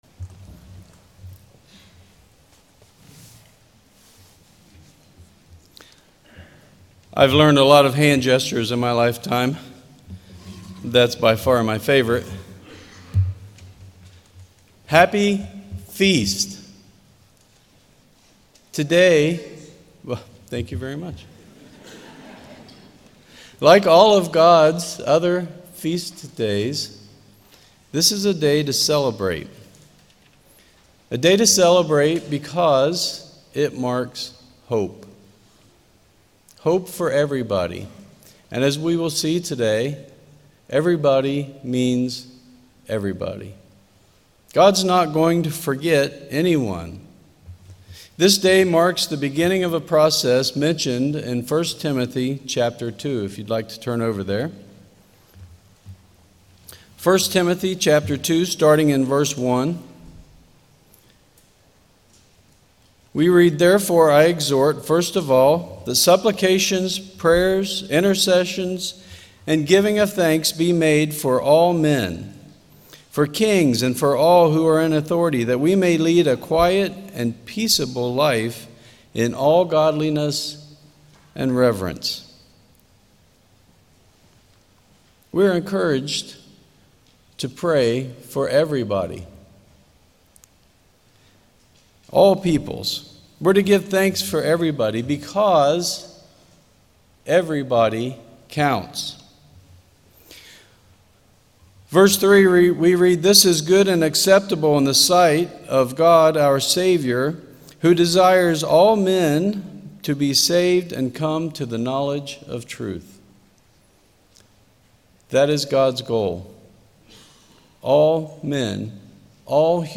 This sermon was given at the St. George, Utah 2022 Feast site.